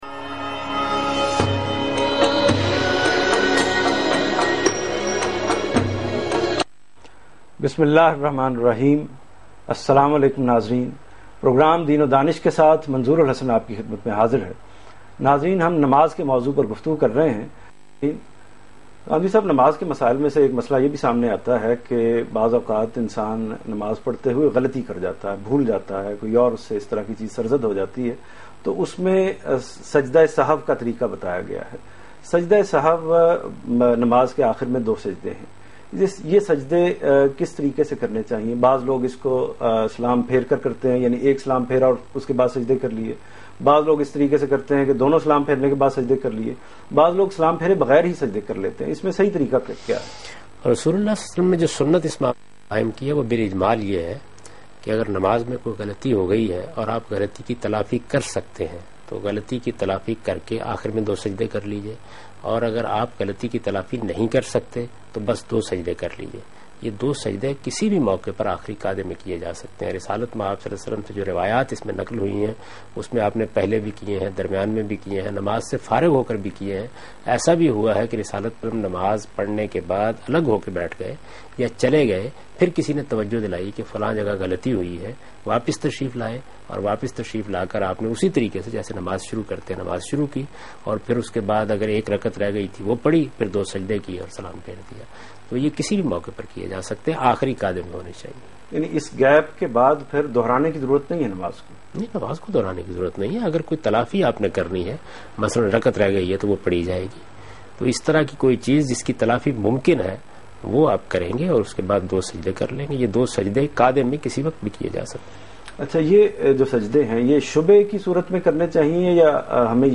Javed Ahmed Ghamidi in Aaj TV Program Deen o Danish Ramzan Special.
جاوید احمد غامدی آج ٹی وی کے پروگرام دین ودانش میں رمضان کے متعلق گفتگو کر رہے ہیں